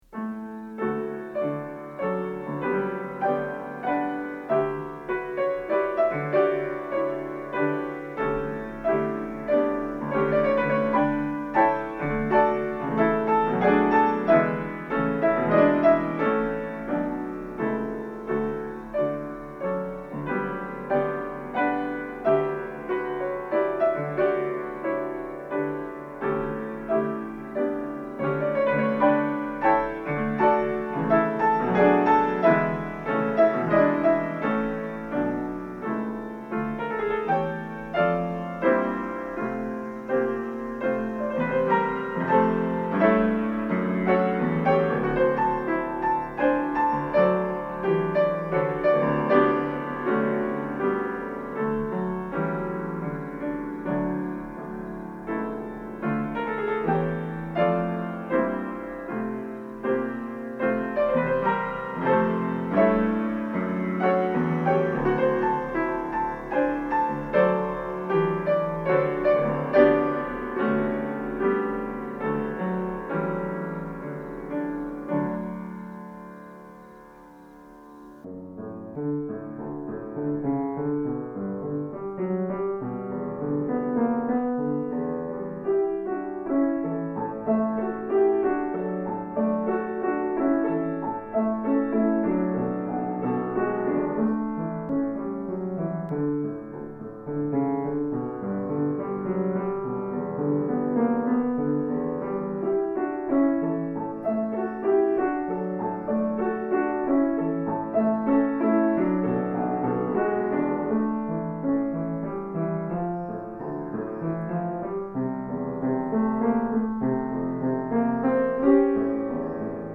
Piano
Style: Classical